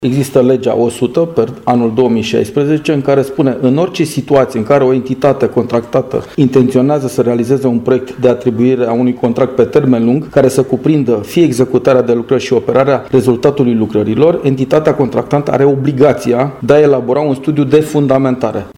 La rândul său, președintele CJ Brașov, Adrian Veștea a spus că și în acest caz totul se circumscrie respectării legislației în vigoare: